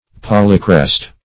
Meaning of polychrest. polychrest synonyms, pronunciation, spelling and more from Free Dictionary.